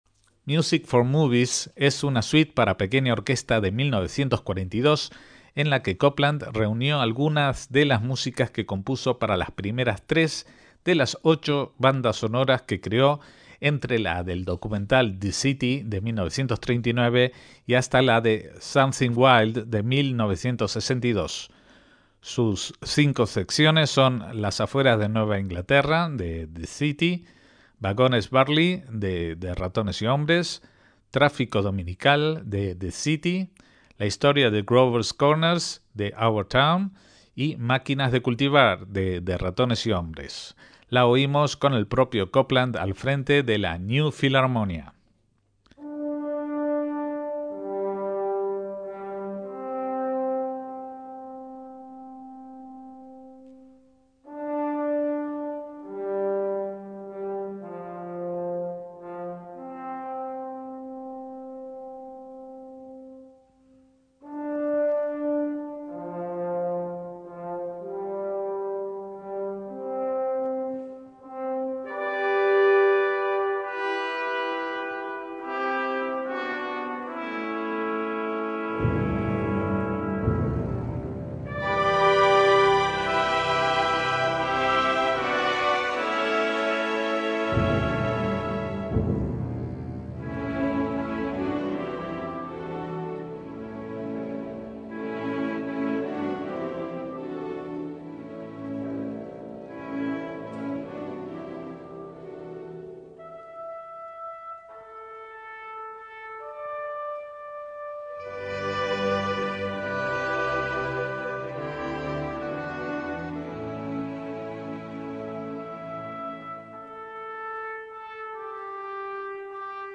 suite orquestal en cinco movimientos